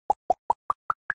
pop_combined.ogg